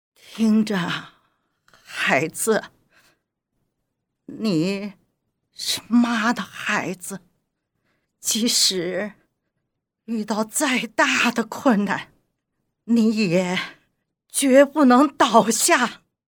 [综合样音集 – 女]
标签 卡通 写实 儿童 样音 生活 动画片 海外
【综合样音集 – 女】精选卡通、真人对话及旁白等多种女声类型，细分为老太太、慈祥母亲、大婶、御姐、女汉子、傻白甜等等，表演风格从含蓄到激昂，从欢乐到悲伤，从阴险到善良，一应俱全。
青年女1青年女人1：约23岁，声音年轻温柔。
青年女人5（卡通）：约25岁，卡通反派女声，使用压嗓子技巧使声音嘶哑，并通过重音起伏加剧，生动塑造了一个犀利的邪恶形象。
中年女人1中年女人1：中年旁白女声，声音温暖厚实。
老者女1老年女人1：60岁女声，声音苍老和蔼。